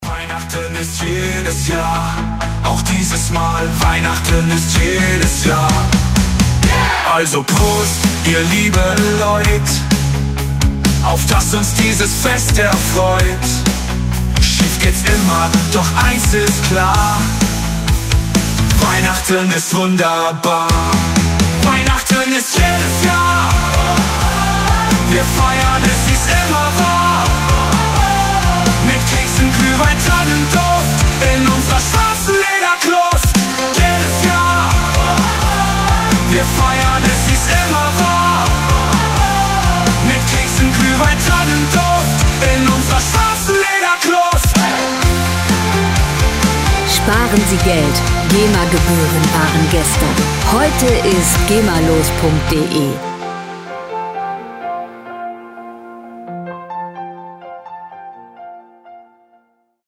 Gemafreie moderne Weihnachtslieder
Musikstil: Fun-Punk
Tempo: 128 bpm
Tonart: E-Dur
Charakter: lustig, spaßig
Instrumentierung: Gesang, E-Gitarre, E-Bass, Drums
Hörprobe [964 KB]